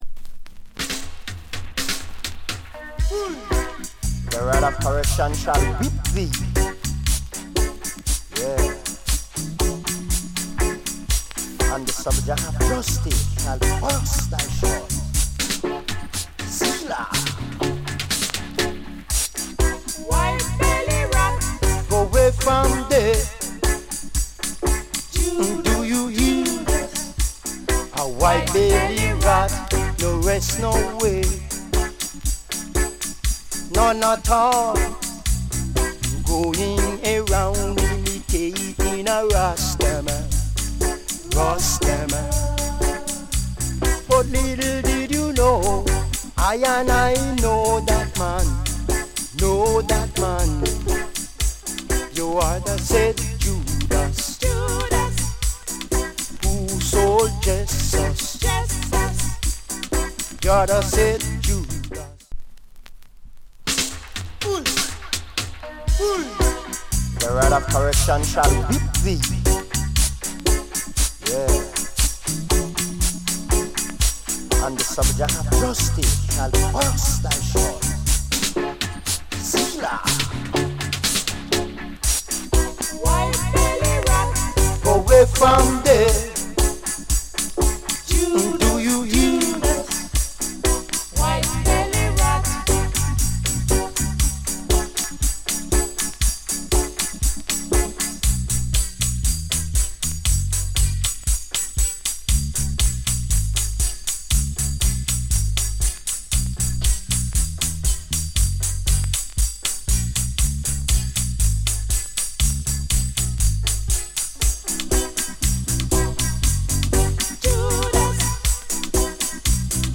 * キンキンしたハイハット、短めのディレイ。